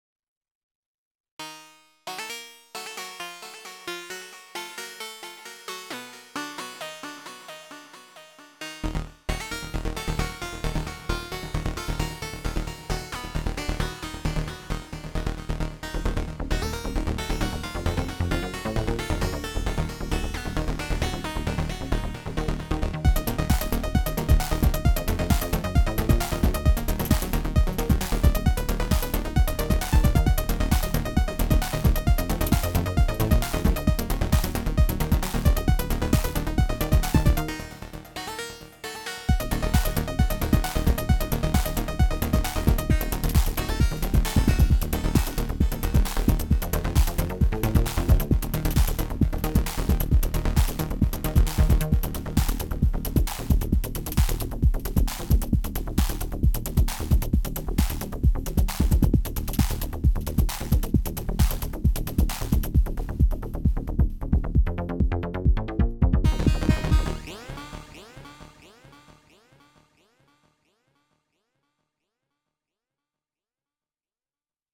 This recording is seven tracks of SY Swarm with the FX block faking compression for the kicks that I probably overdid.
Kick has a sweeping LPF to get it close.
The melodic and bass tracks are bog standard synthesis. I just had fun using a Hungarian scale in D#.